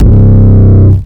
archived music/fl studio/drumkits/goodtakimu drumkit/808s